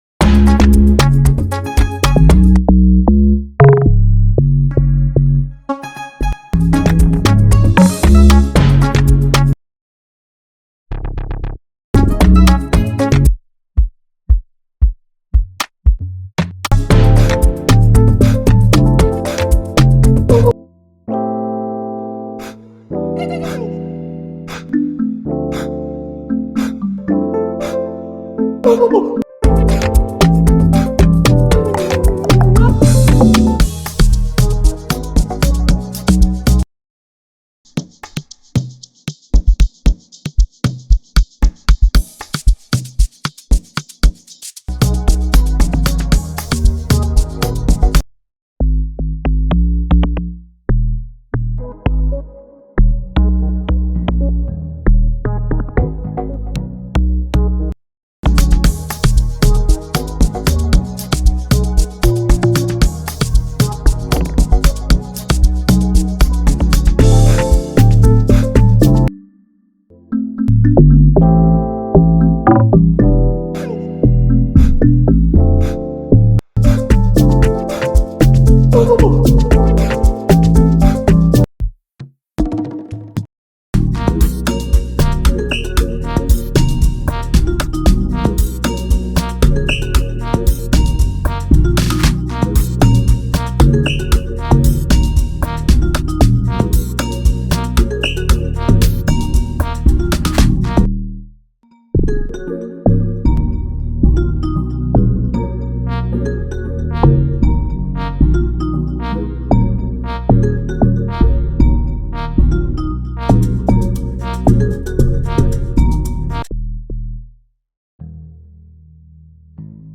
Over 2500+ Sounds and Samples in the bundle.
Afropiano All-In-One:
– 200+ Drum Percussion and Melody Loops (Stems Inclusive)